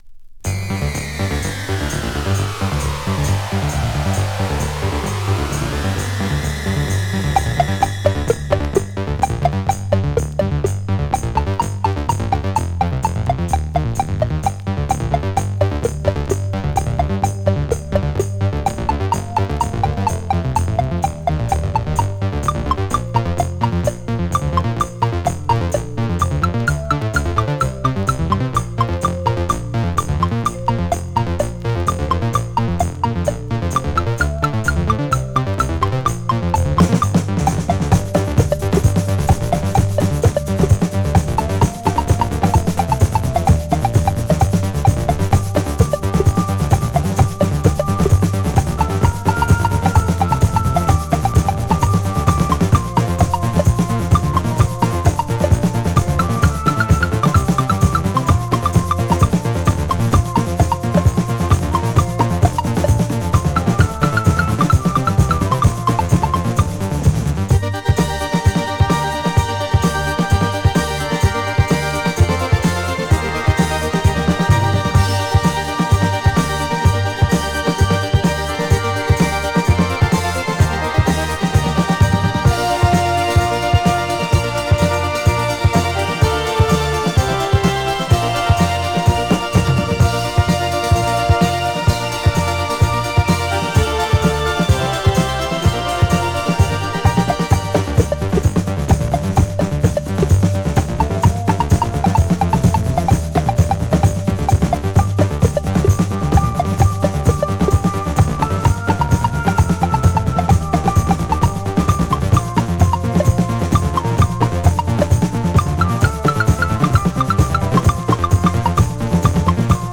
Жанр: Synth-pop